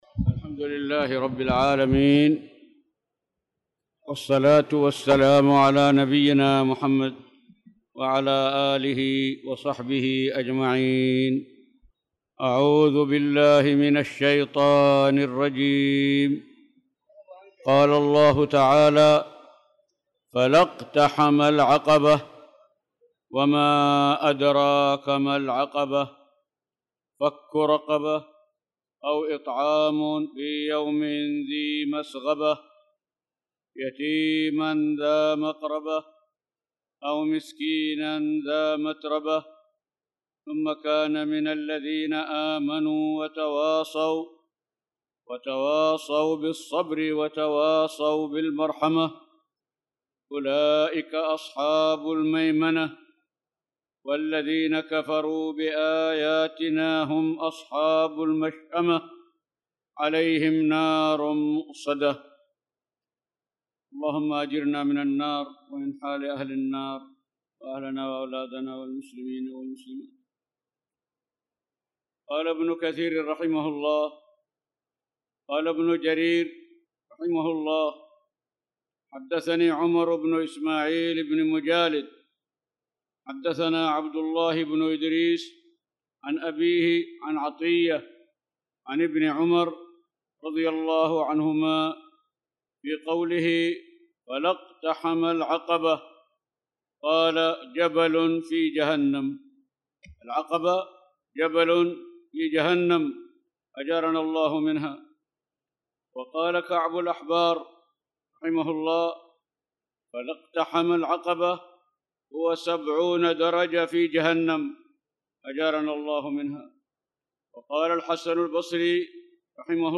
تاريخ النشر ٢٨ شعبان ١٤٣٧ هـ المكان: المسجد الحرام الشيخ